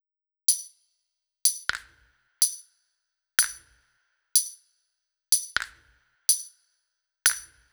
Bleep Hop Perc Loop.wav